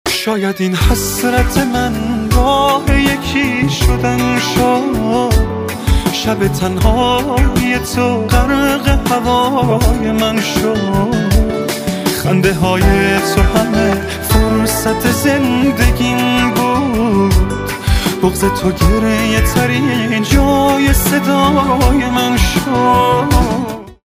رینگتون لطیف و احساسی با کلام